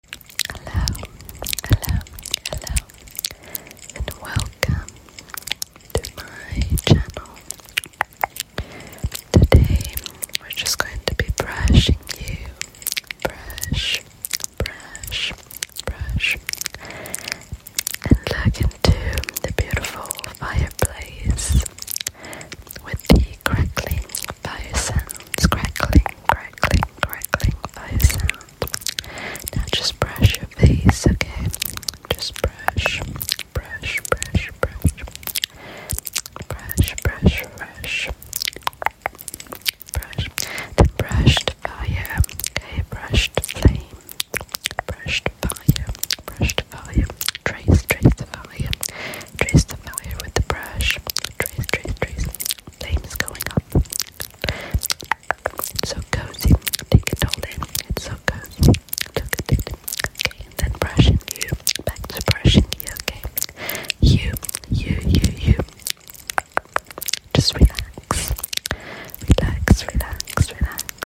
Lazy but cozy ASMR video sound effects free download
Lazy but cozy ASMR video today- tingly whispers and a crackling “fireplace” 🔥🕯